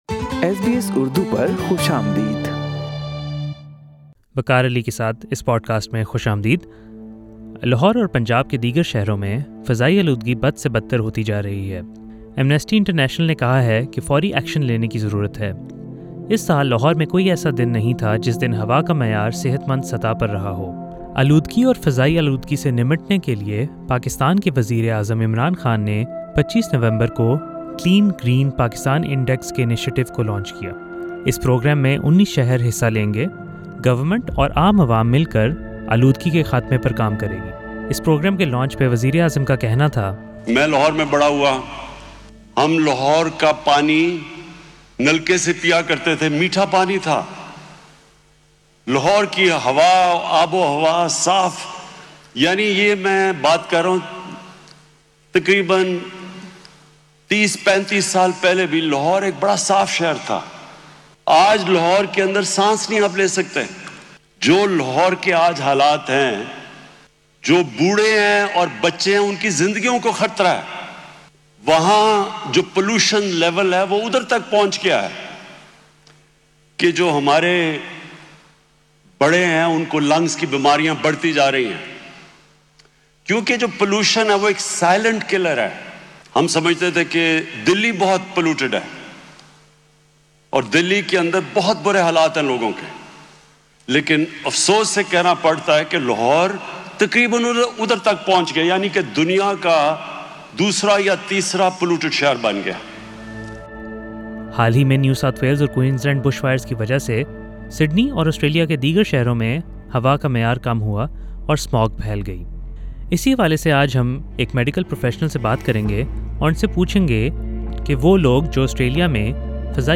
Air quality levels across Lahore and Punjab over the last few weeks has crossed hazardous levels multiple times which is causing severe health issues. SBS Urdu talked to a medical professional to find out what precautions are necessary if you plan to travel to Pakistan.